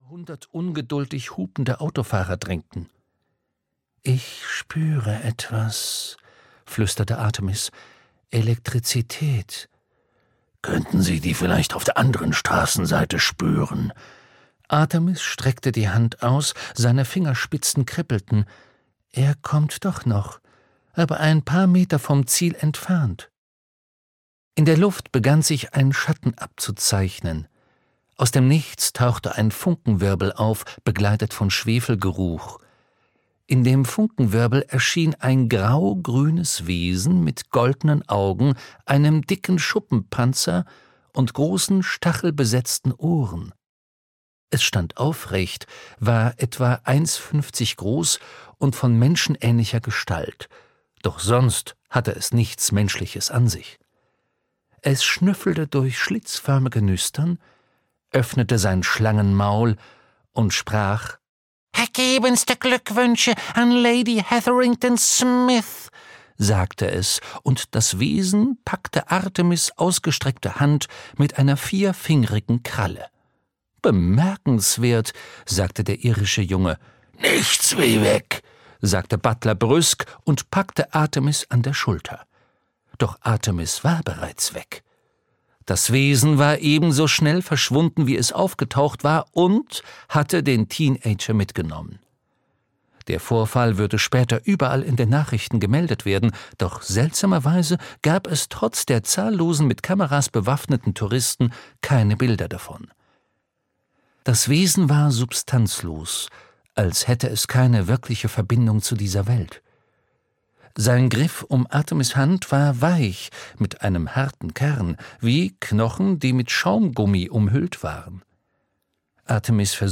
Artemis Fowl - Die verlorene Kolonie (Ein Artemis-Fowl-Roman 5) - Eoin Colfer - Hörbuch